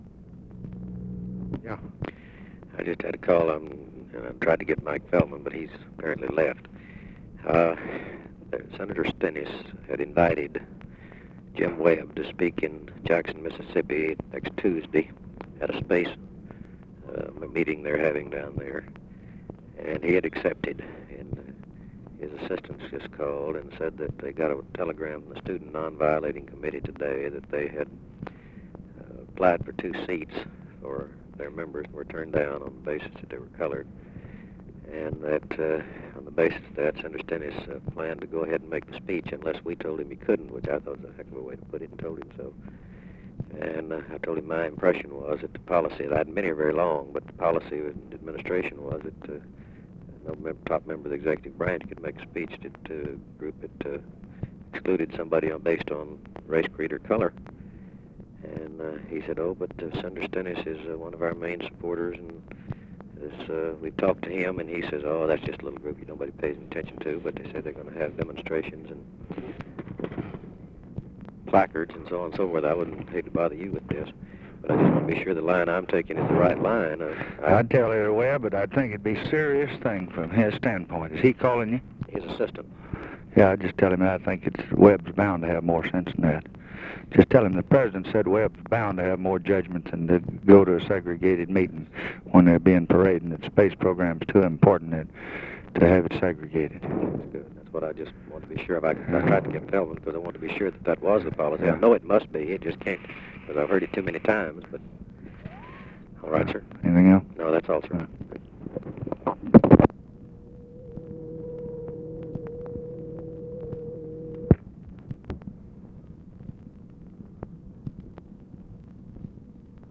Conversation with WALTER JENKINS, January 24, 1964
Secret White House Tapes